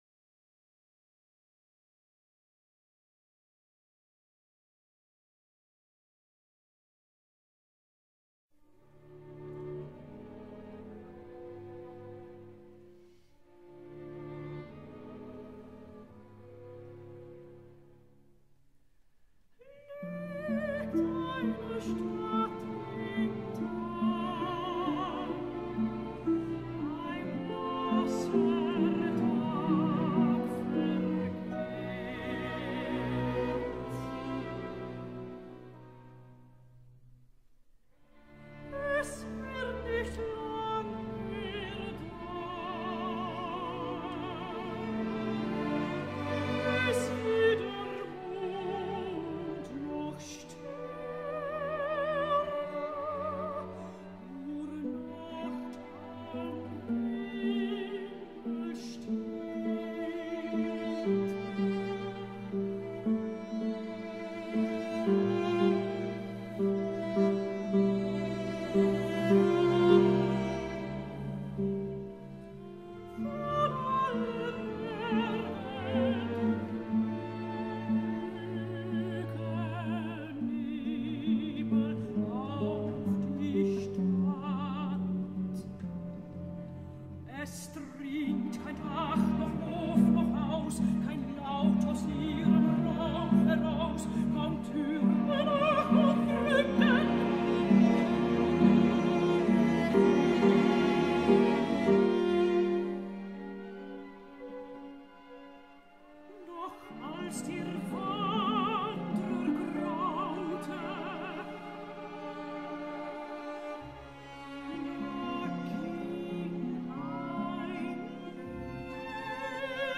alma-mahler-complete-songs.mp3